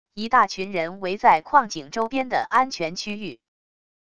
一大群人围在矿井周边的安全区域wav音频